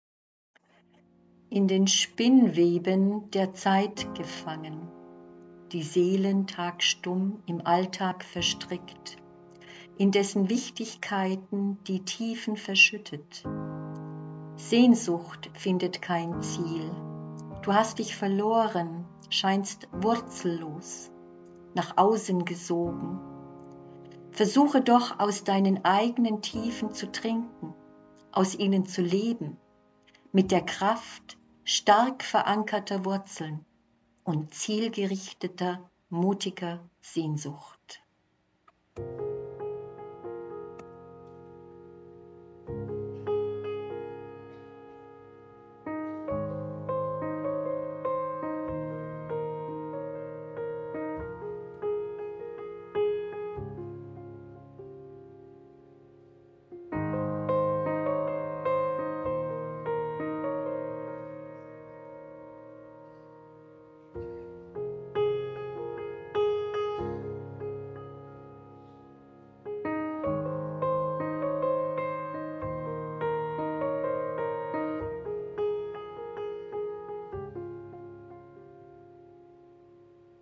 Autorenlesung